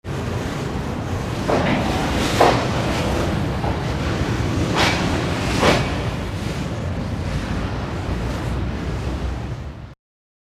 Machine
Furnace(164K)